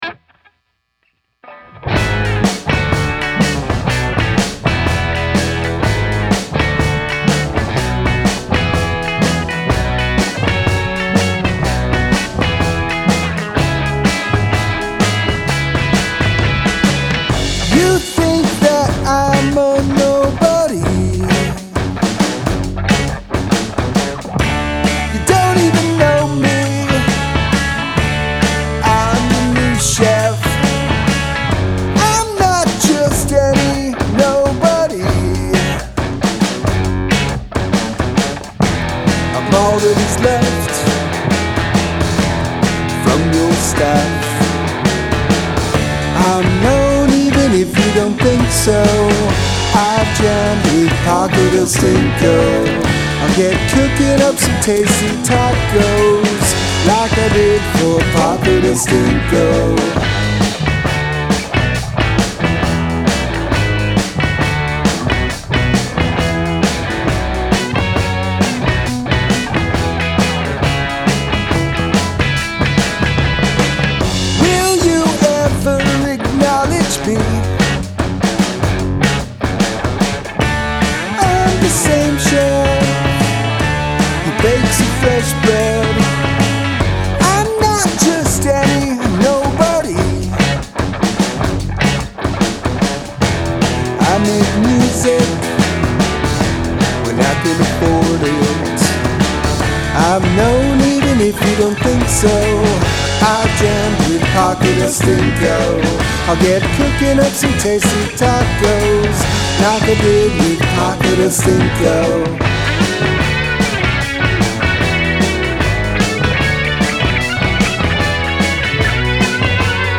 I like the drums.
I like the rhythm guitars.